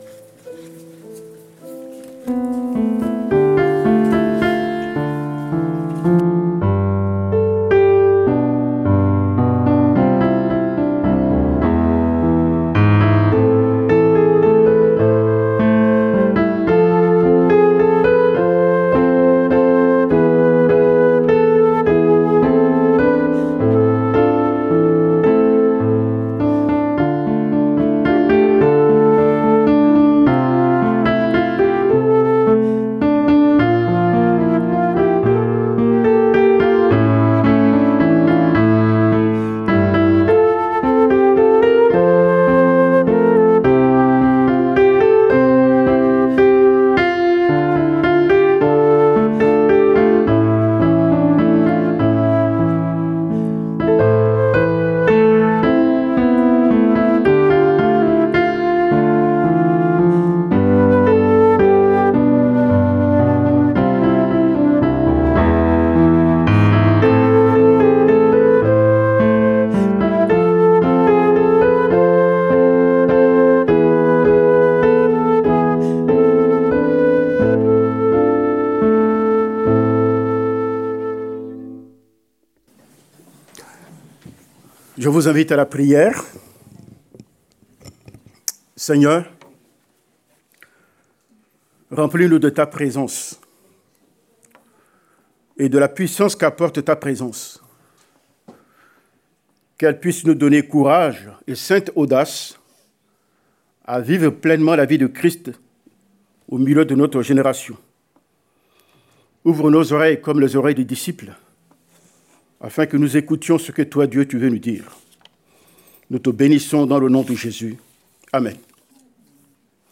Message du 28 mai 2023.